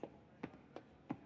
State Alchemist's Footsteps.wav